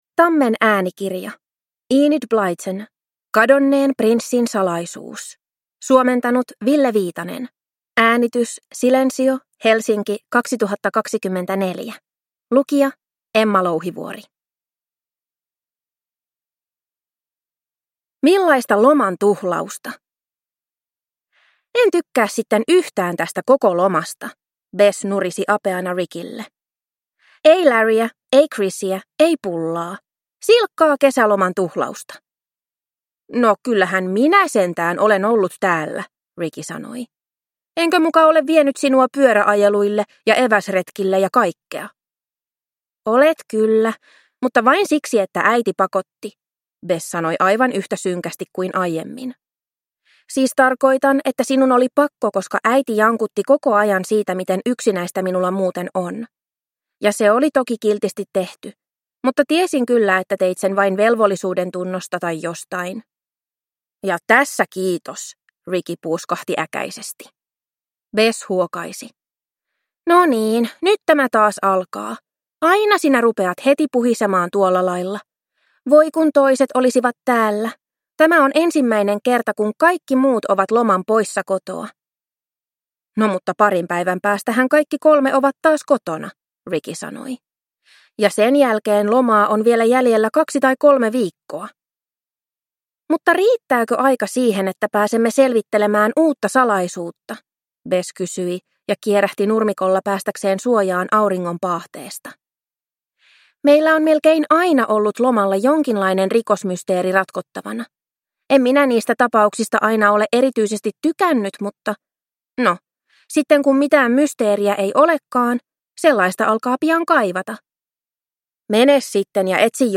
Kadonneen prinssin salaisuus – Ljudbok